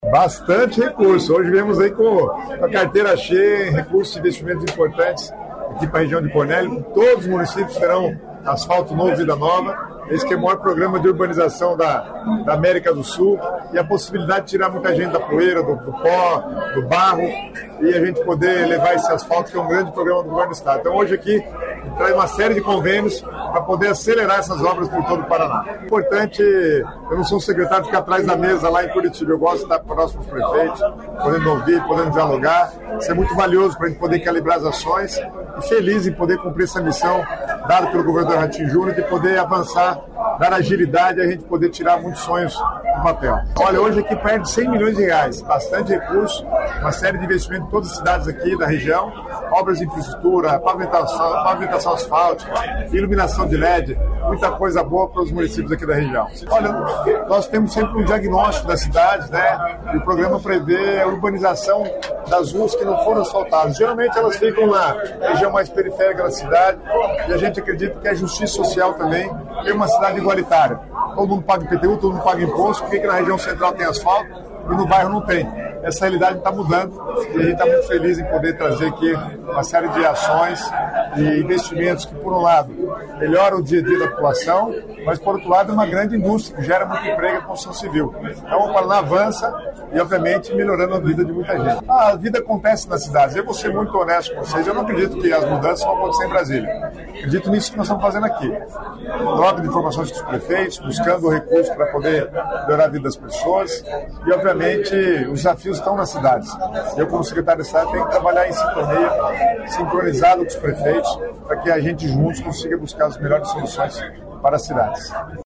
Sonora do secretário Estadual das Cidades, Guto Silva, sobre as liberações desta sexta pelo Asfalto Novo, Vida Nova